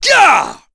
Chase-Vox_Attack3.wav